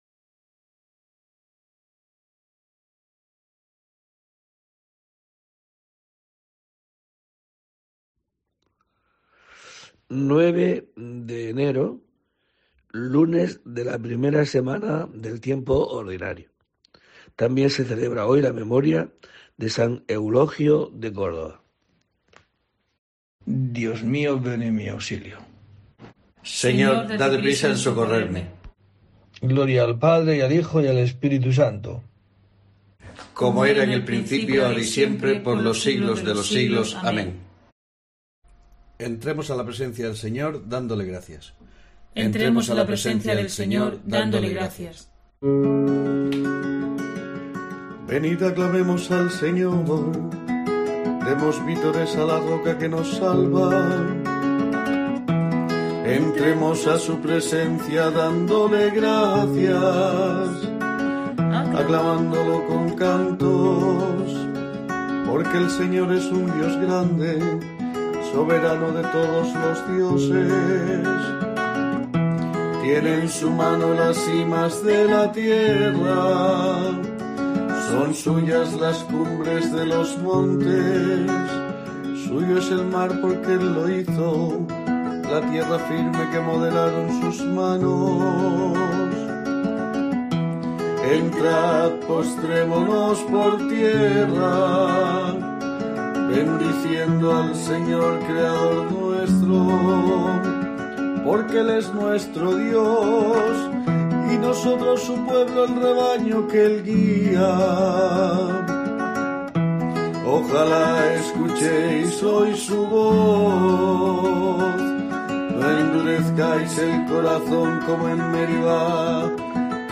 09 de enero: COPE te trae el rezo diario de los Laudes para acompañarte